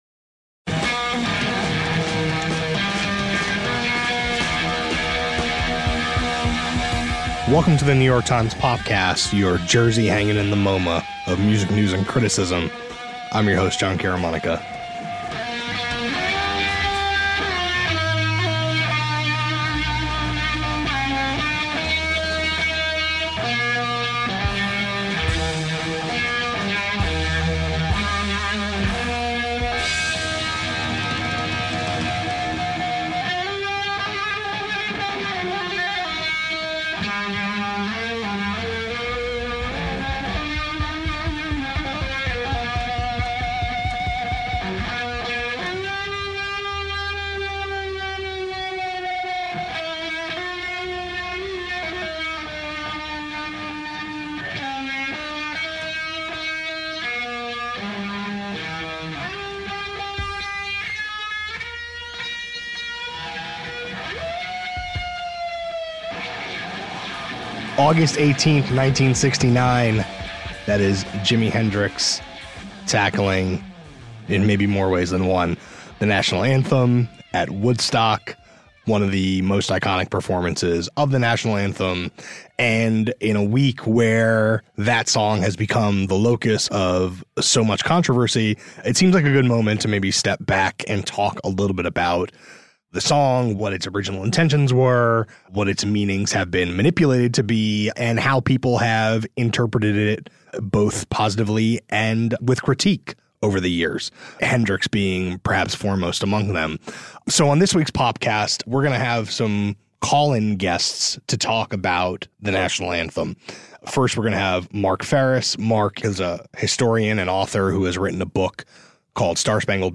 “The Star-Spangled Banner” has long been a site of dissent and disagreement. A conversation about the history of the anthem, and those who have protested it.